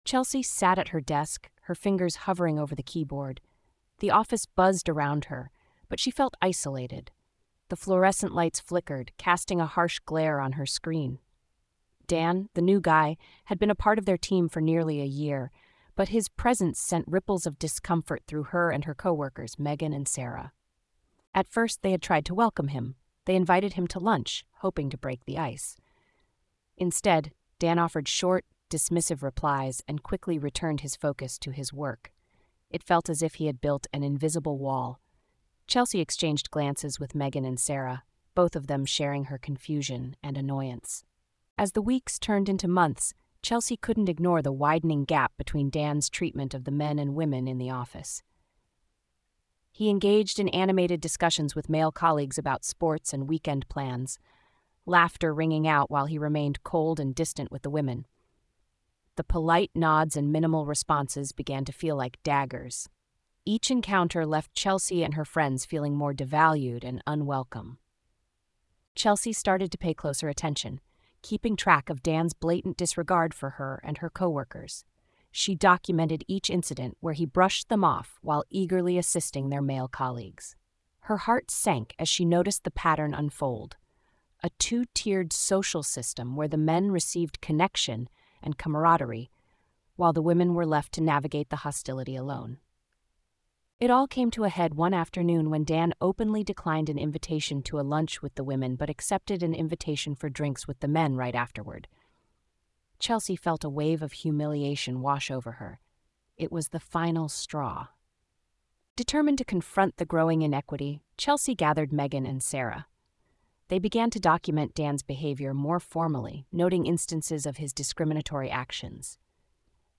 In this audiobook, the corporate world becomes a battlefield, and the line between loyalty and survival grows thinner with each revelation.